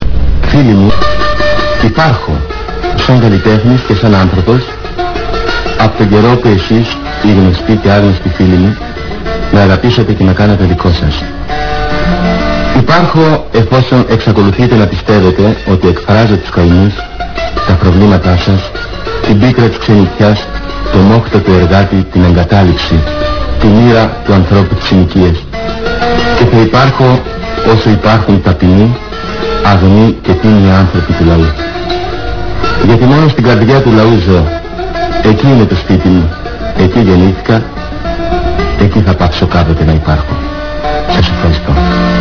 STELIOS WELCOMES YOU